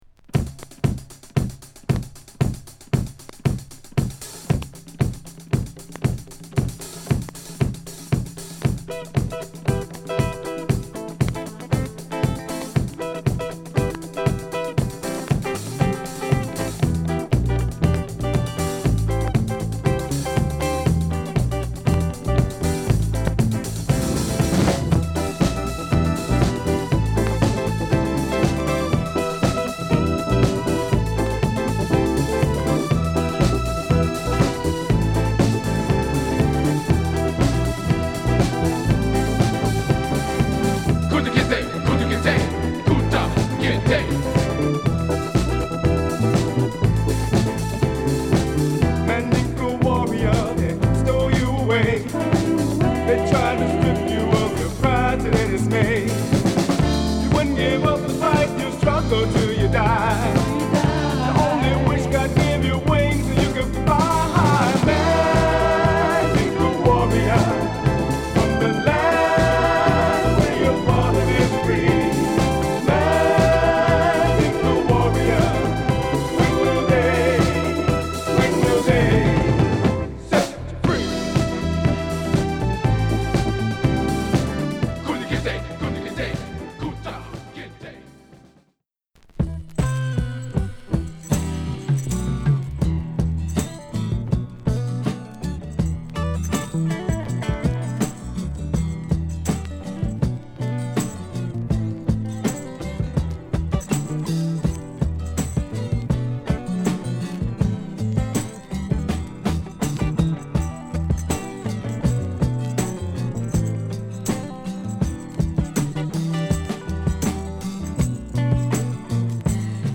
＊SideA頭に傷有り。数回プチ入ります。試聴ファイルでご確認下さい。